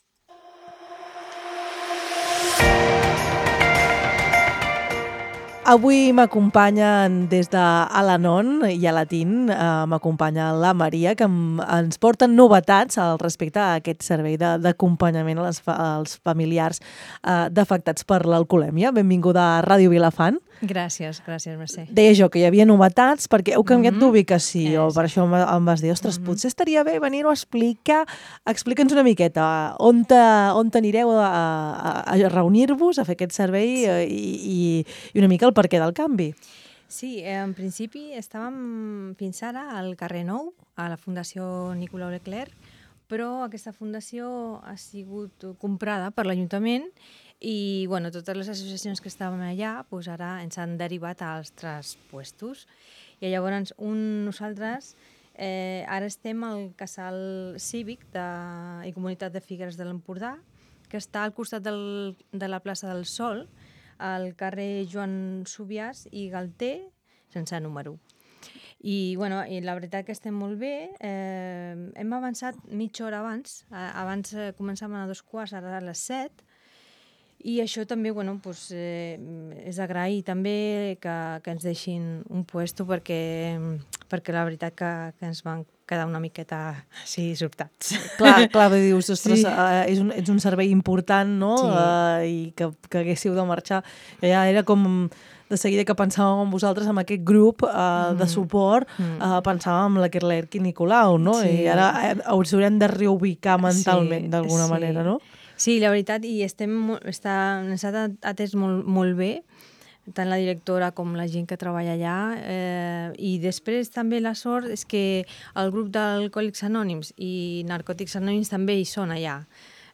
LVDM - ENTREVISTA - ALANON NOVA UBICACIO 13 NOVEMBRE 24~0.mp3